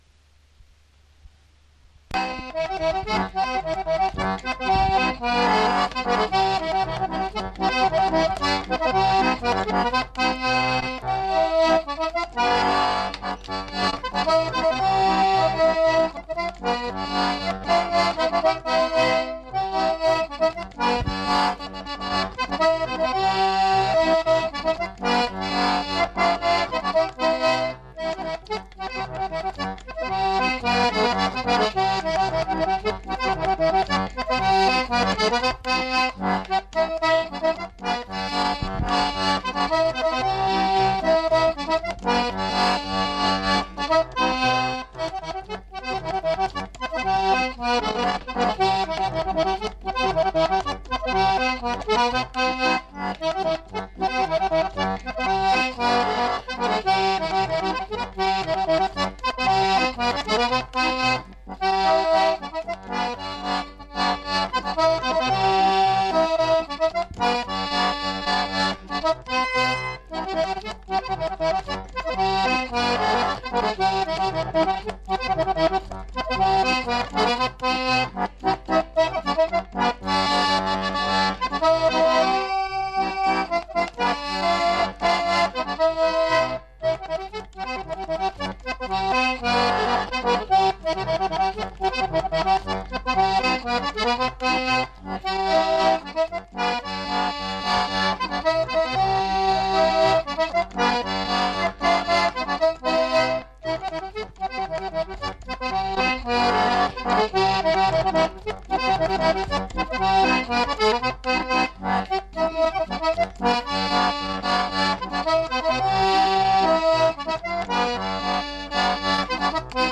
Aire culturelle : Savès
Département : Gers
Genre : morceau instrumental
Instrument de musique : accordéon diatonique
Danse : polka